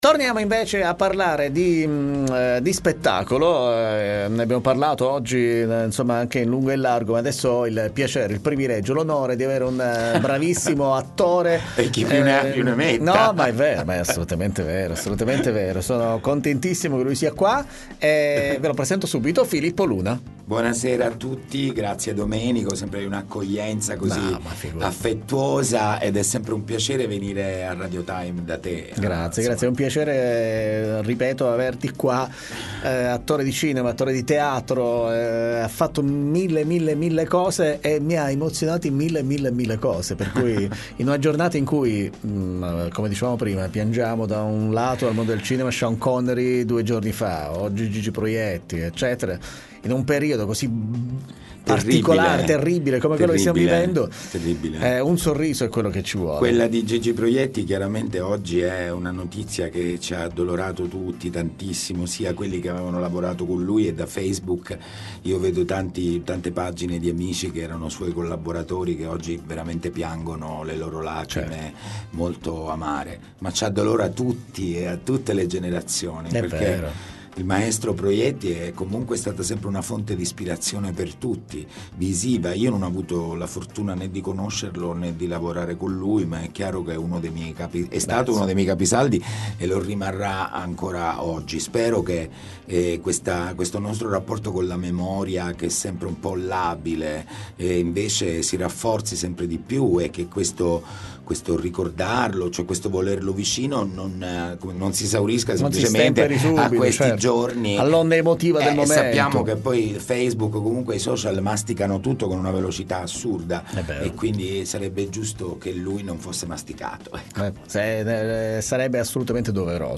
Doc Time intervista